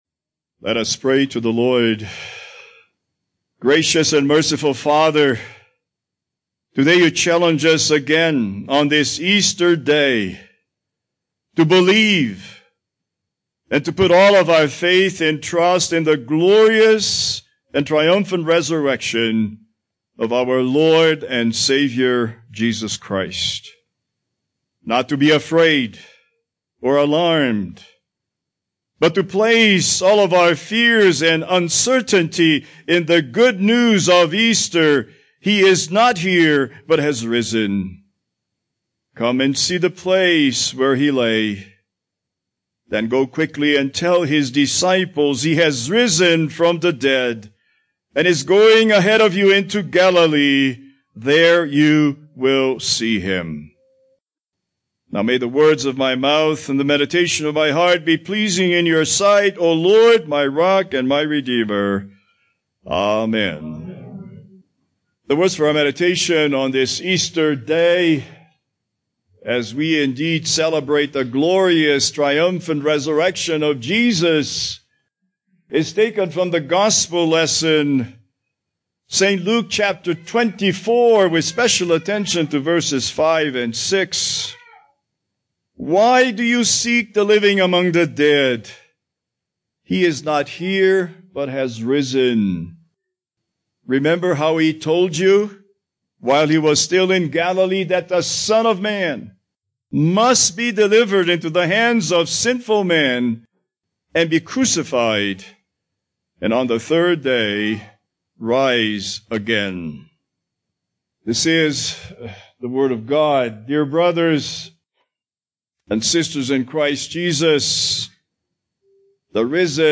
Series: Holiday Sermons Passage: Luke 24:1-12 Service Type: Easter Service « My God